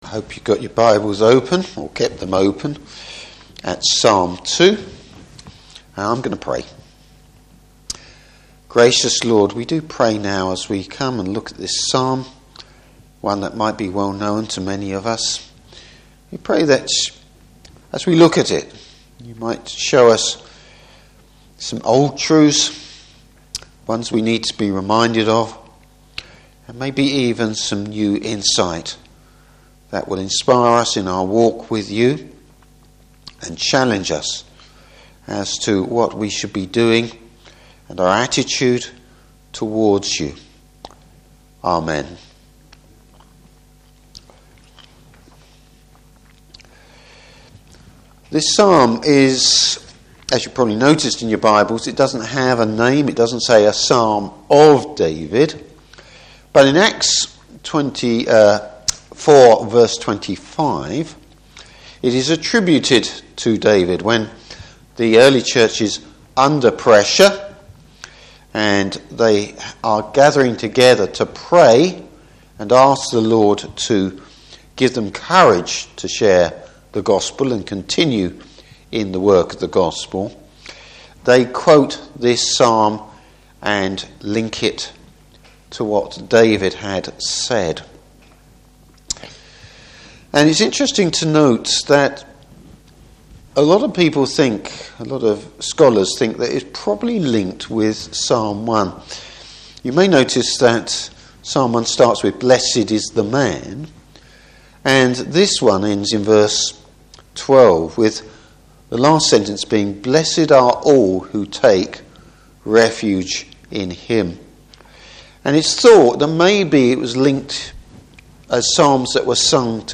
Service Type: Evening Service Showing reverence towards God.